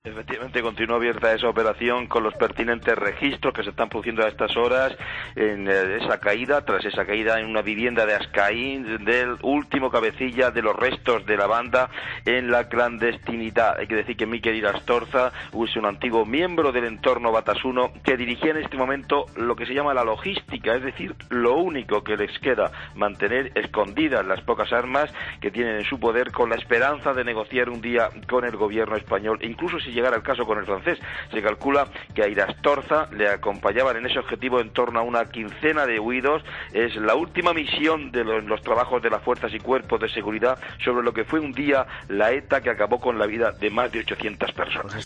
AUDIO: Detenido en Francia el último jefe de ETA, Mikel Irastorza. Crónica